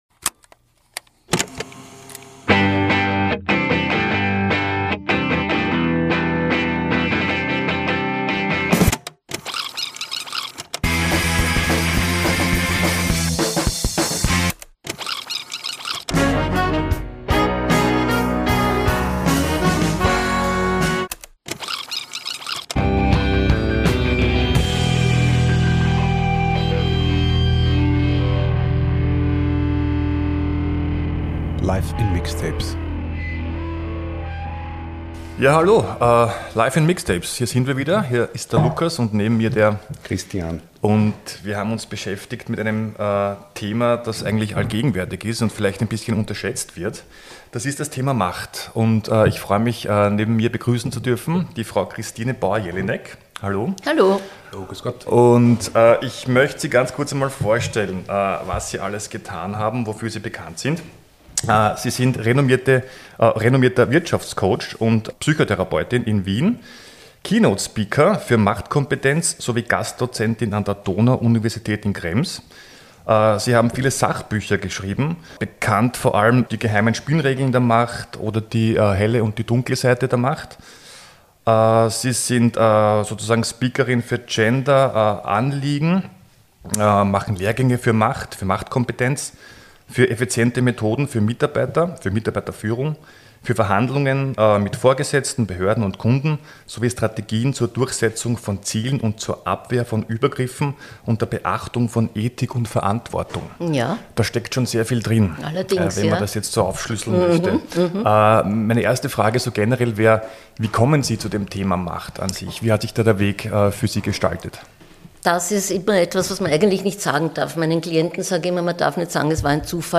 Wir sprechen mit ihr über Macht in der Gesellschaft, Macht in der Politik, Macht am Arbeitsplatz und Macht in Zweierbeziehungen. Und hören dabei powervolle Musik.